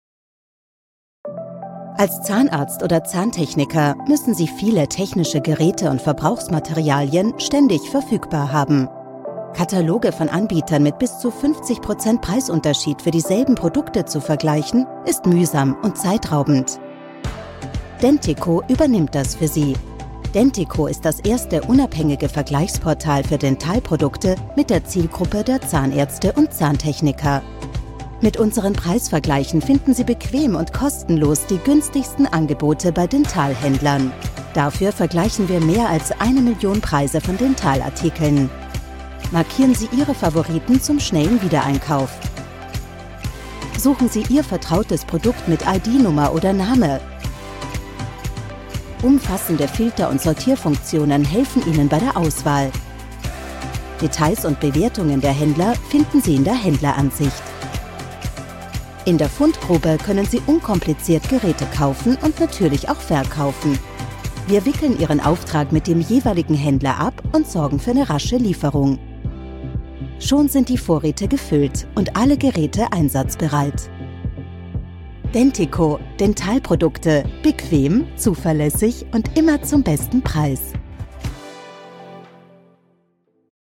German Voice Over Narrator for Austria & Germany - Broadcast Quality Studio
German voice over demos: corporate videos, demonstration videos, corporate films
Explainer video Dentiqo
erklaervideo_dentiqo.mp3